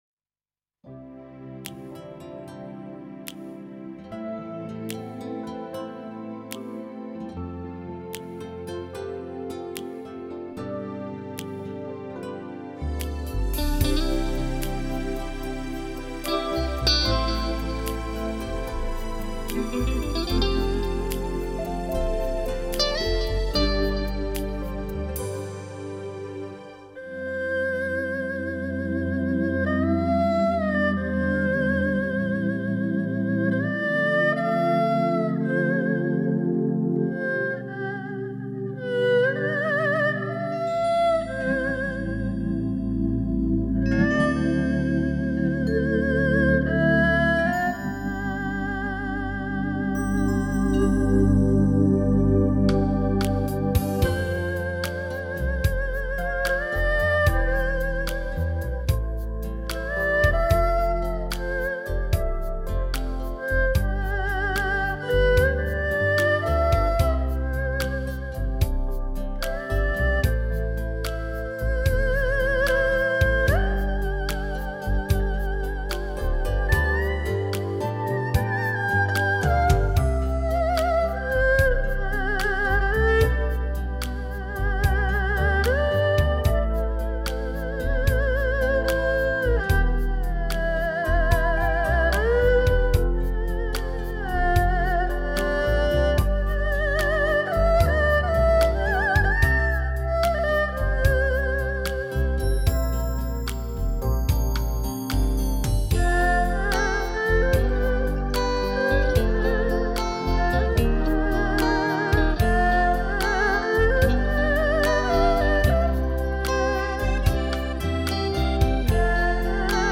等经典电影中的经典曲目，用二胡、古筝、巴乌与琵琶为您追忆不一样的精选片段。